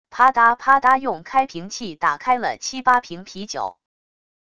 啪嗒啪嗒用开瓶器打开了七八瓶啤酒wav音频